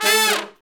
Index of /90_sSampleCDs/Roland LCDP06 Brass Sections/BRS_R&R Horns/BRS_R&R Falls